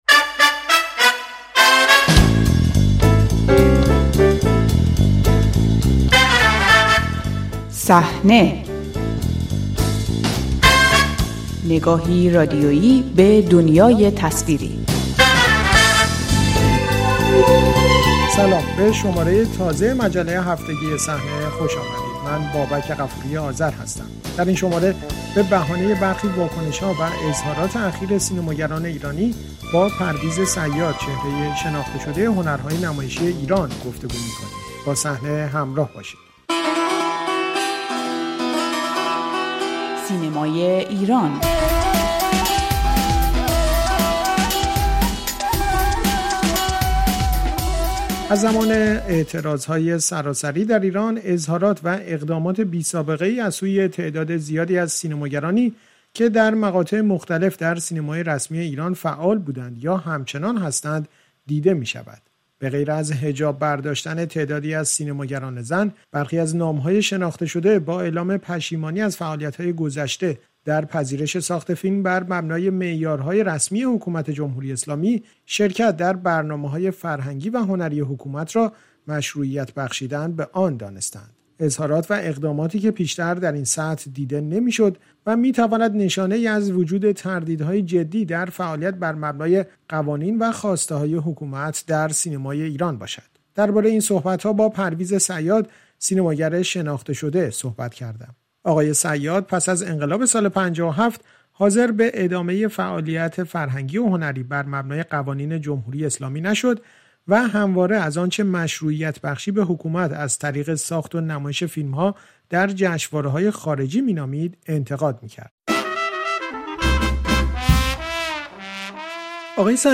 چالش مشروعیت سینمای جمهوری اسلامی؛ گفت‌وگو با پرویز صیاد